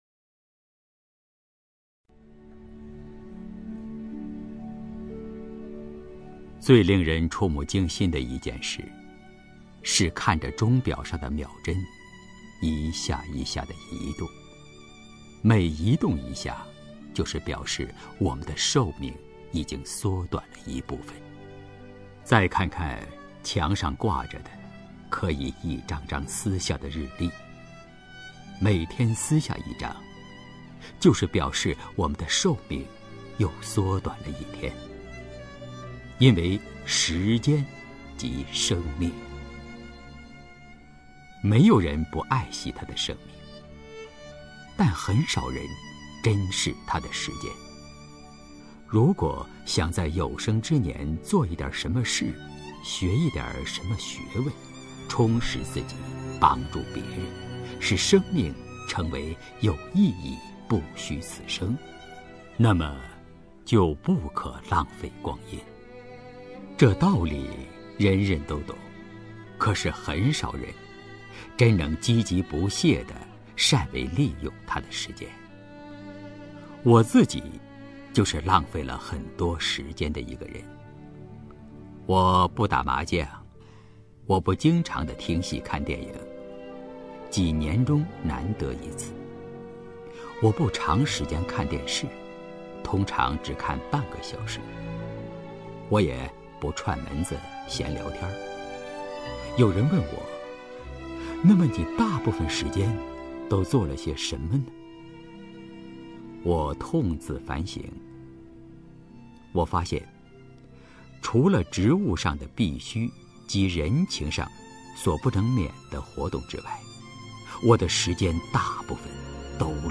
首页 视听 名家朗诵欣赏 刘纪宏
刘纪宏朗诵：《时间即生命》(梁实秋)